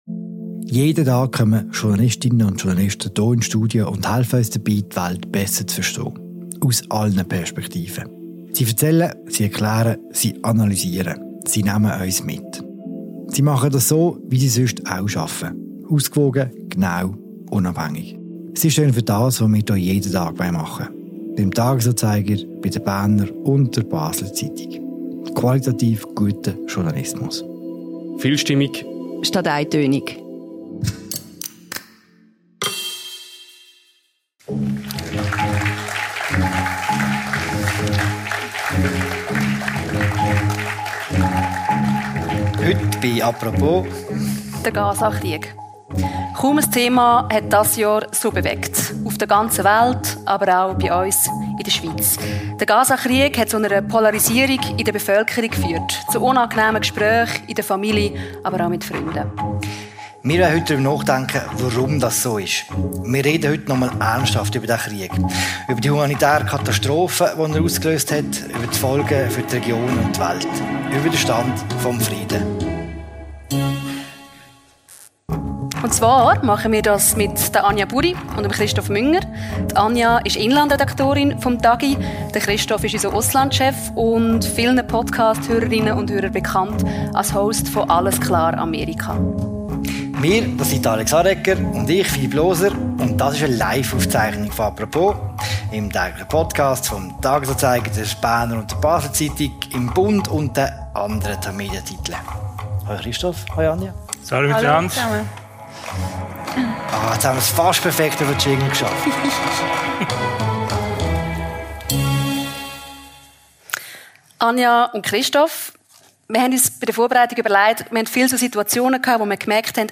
Die Aufzeichnung des Livegesprächs hören Sie jetzt als neue Folge des täglichen Podcasts «Apropos».